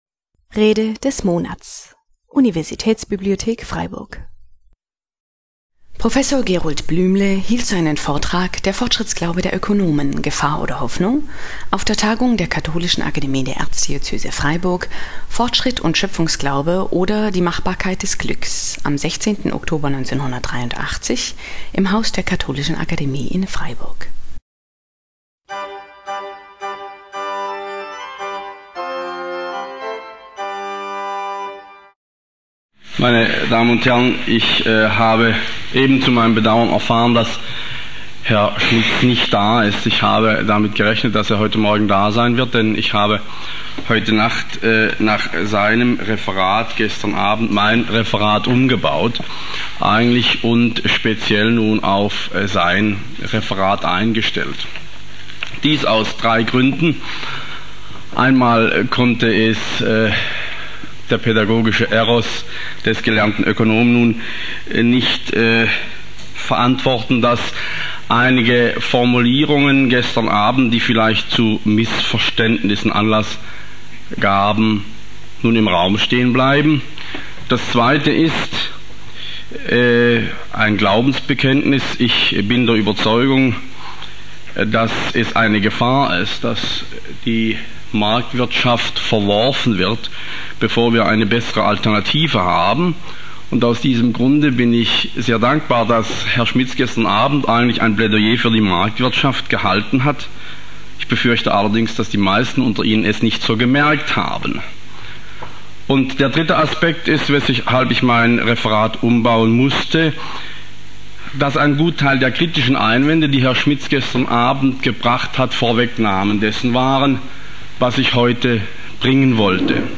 Der Fortschrittsglaube der Ökonomen : Gefahr oder Hoffnung? (1983) - Rede des Monats - Religion und Theologie - Religion und Theologie - Kategorien - Videoportal Universität Freiburg